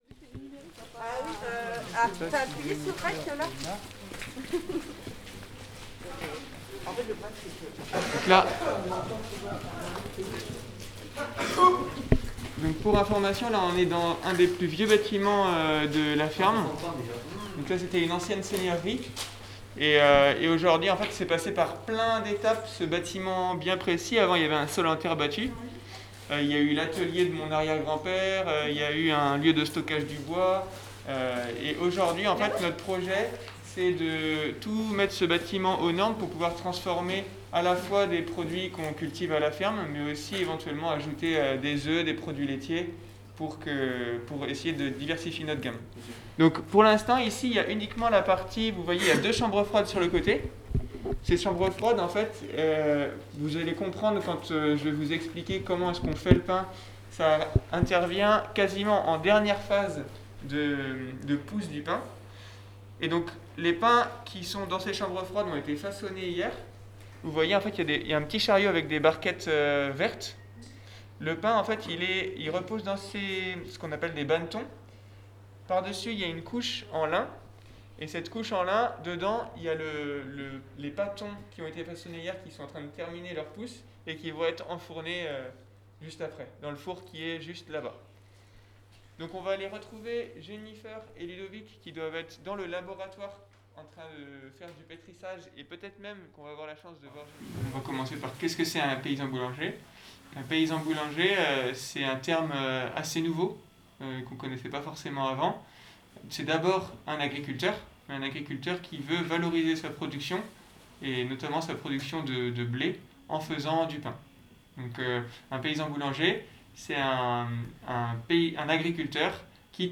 Les élèves ont réalisé.e.s toutes les prises de sons et ont dérushé les fichiers bruts pour nous offrir une visite en 4 chapitres.